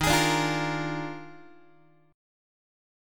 Listen to EbM7sus2sus4 strummed